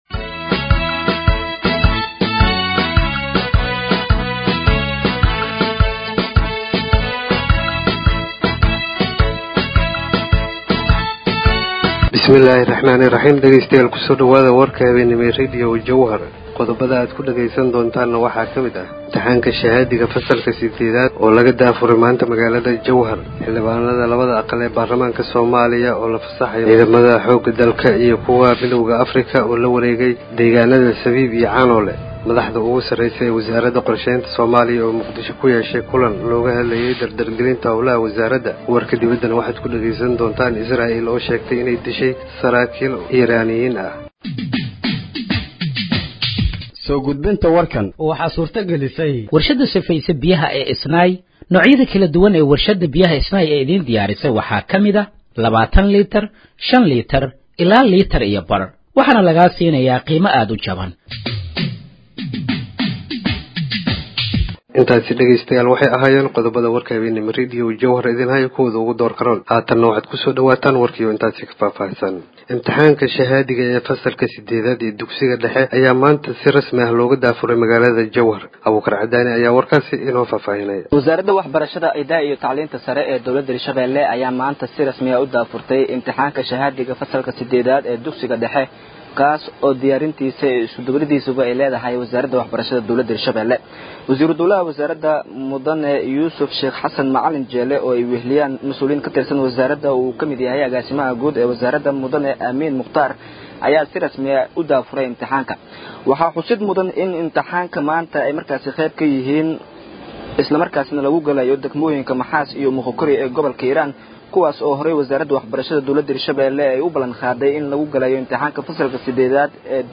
Dhageeyso Warka Habeenimo ee Radiojowhar 21/06/2025
Halkaan Hoose ka Dhageeyso Warka Habeenimo ee Radiojowhar